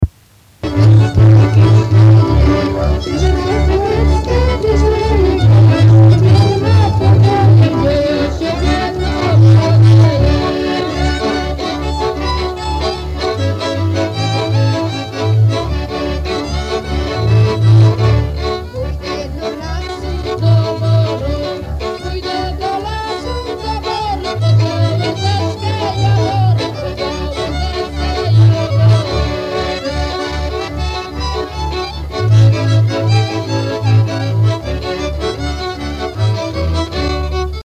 Wyszła chmurecka, dysc leje – Żeńska Kapela Ludowa Zagłębianki
Nagranie archiwalne